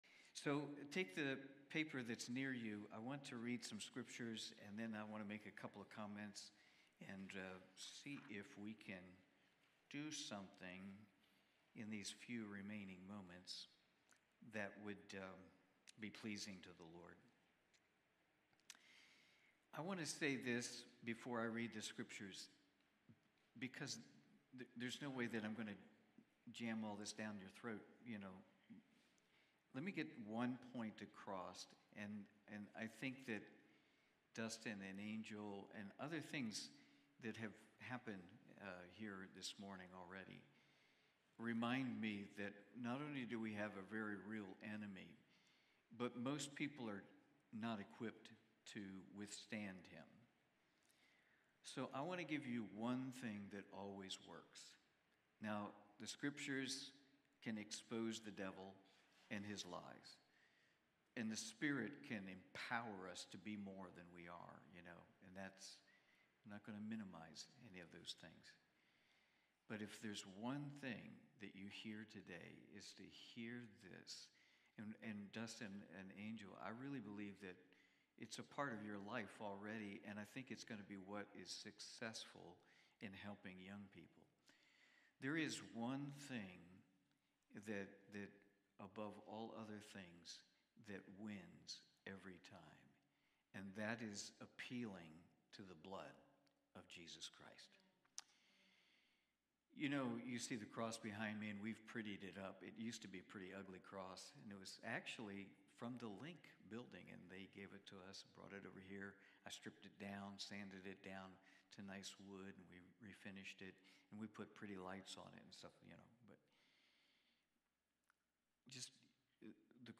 1 John Watch Listen Save Cornerstone Fellowship Sunday morning service, livestreamed from Wormleysburg, PA.